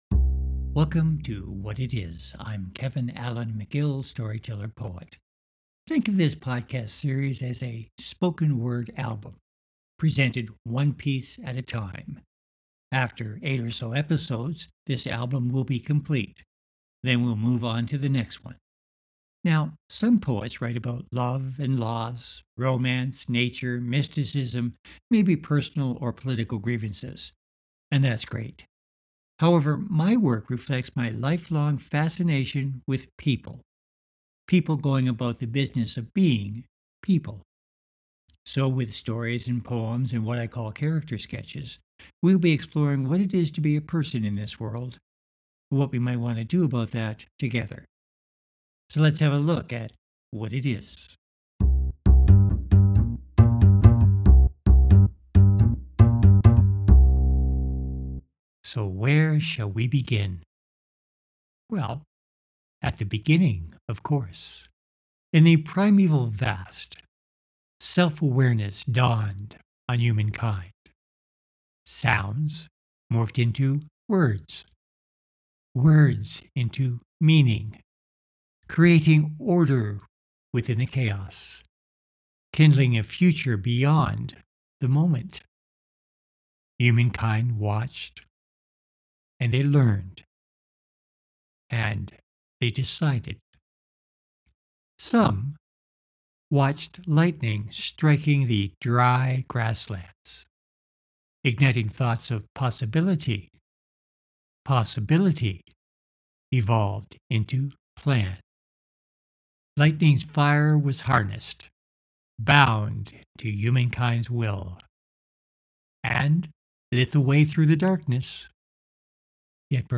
Each episode of this series will combine with the next to create a Spoken Word Album.